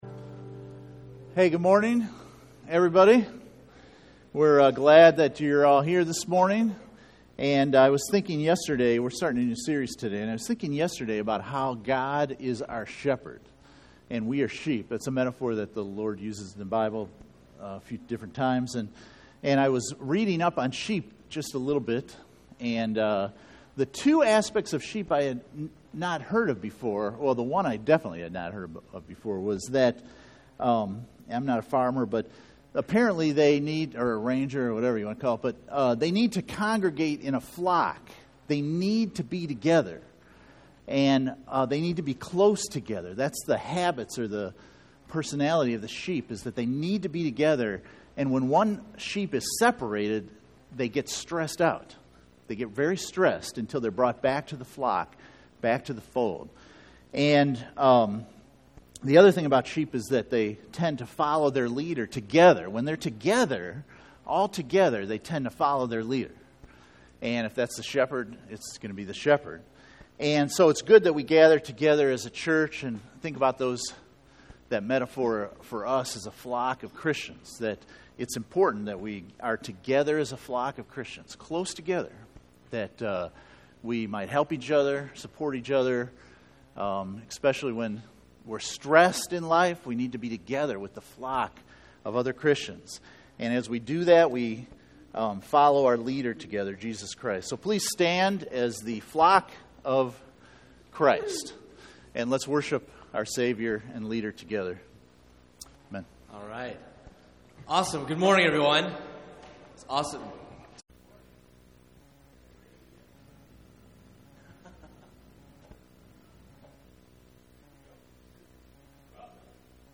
Pastor's Heart Series Service Type: Sunday Morning %todo_render% « Faithwalkers Conference Testimonies Pastor’s Heart for the Church